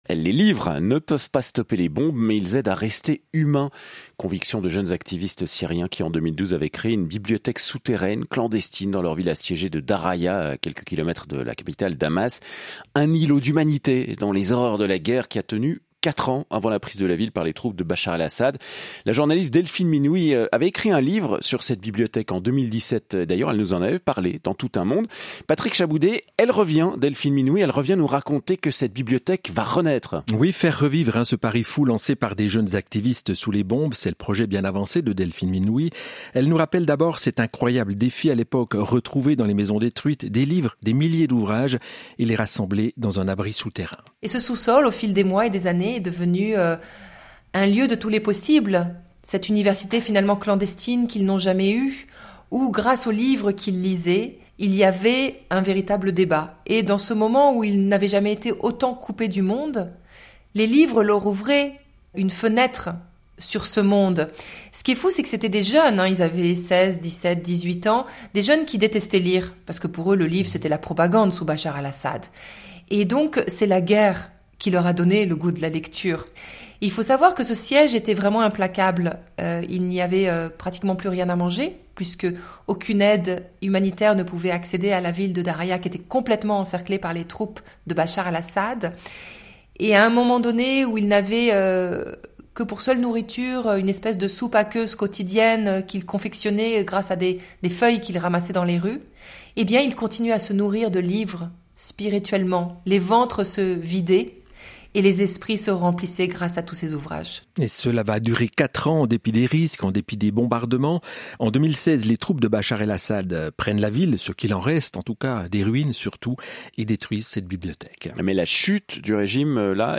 Les-livres-ne-stoppent-pas-les-bombes-mais-ils-aident-rester-humain-interview-de-Delphine-Minoui-1.mp3